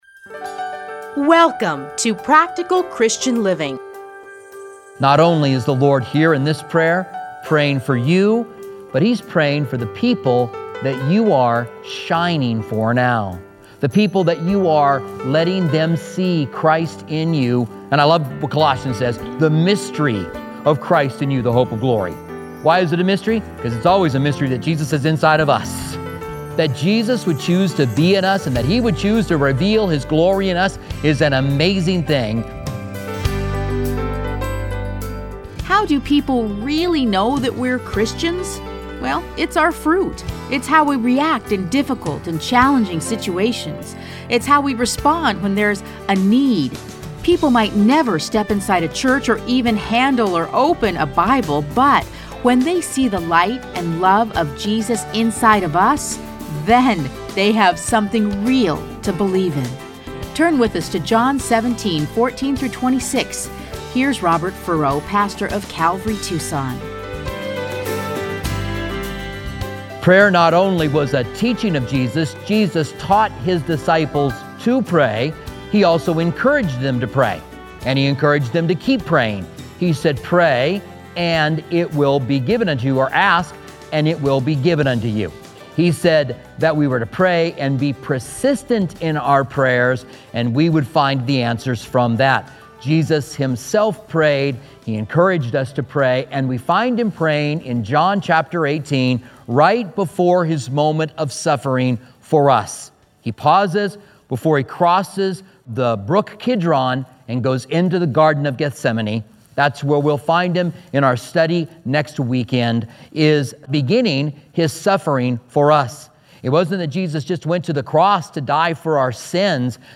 Listen to a teaching from John John 17:14-26.